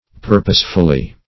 Pur"pose*ful*ly, adv.